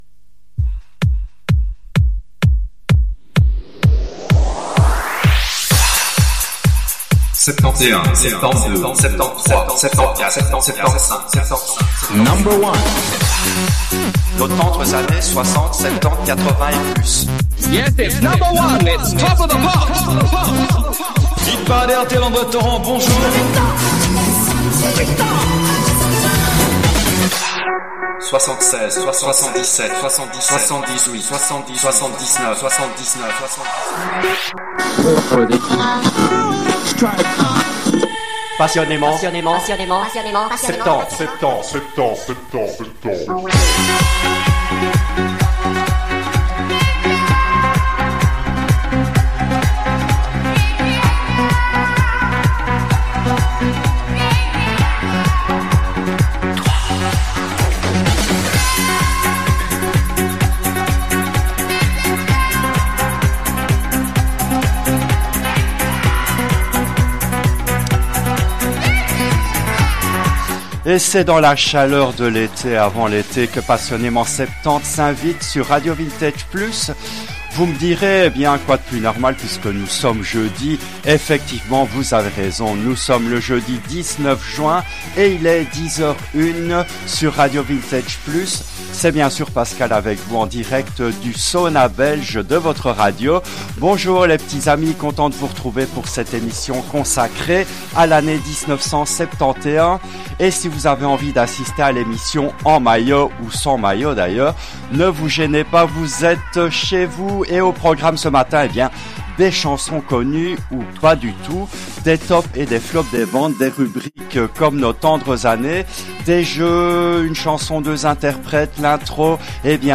L’émission a été diffusée en direct le jeudi 19 juin 2025 à 10h depuis les studios belges de RADIO RV+.